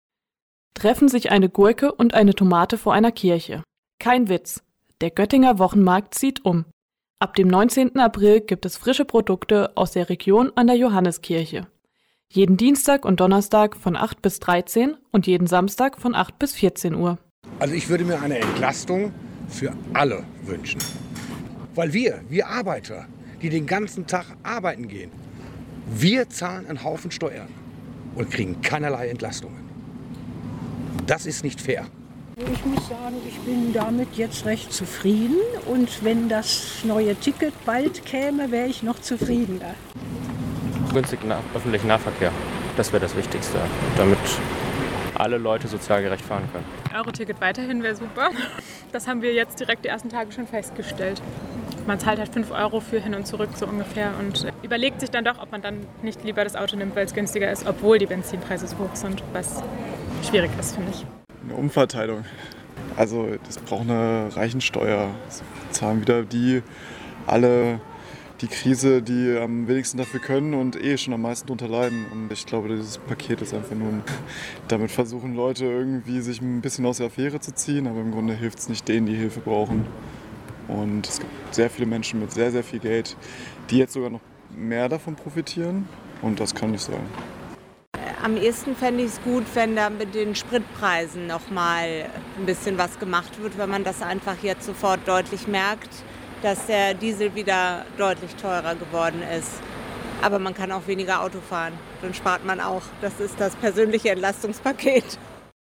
waren in der Innenstadt unterwegs und haben die Göttinger*innen gefragt, in welchen Bereichen sie sich Unterstützung wünschen würden.